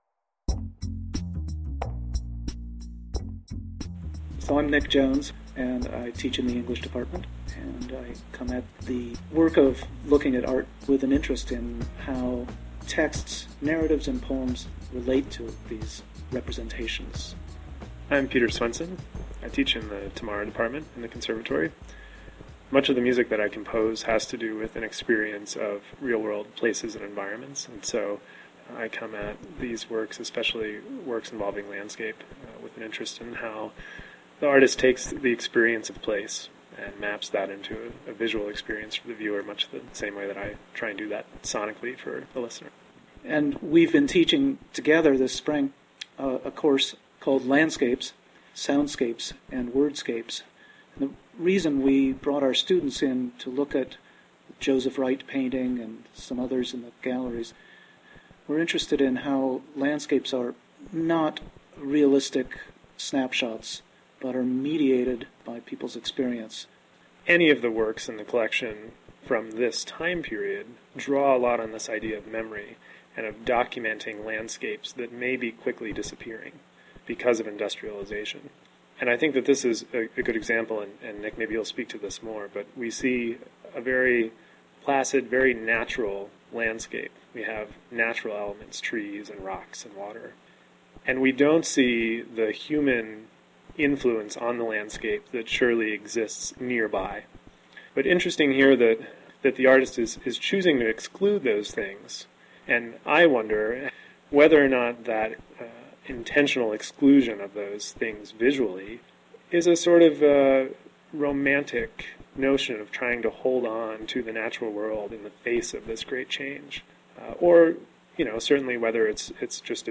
Here, professors and students alike discuss collection works – often through the lens of their individual disciplines.